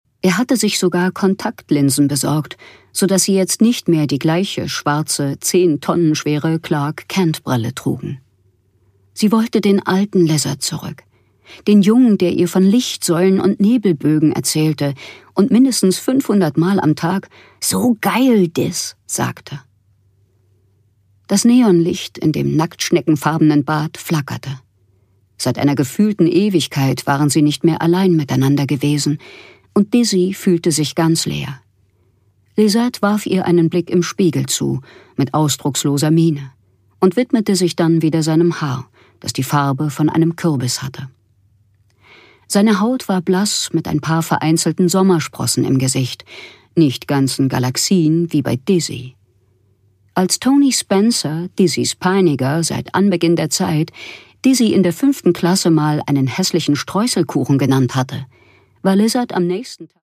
Jandy Nelson: Wenn unsere Welt kippt (Ungekürzte Lesung)
Produkttyp: Hörbuch-Download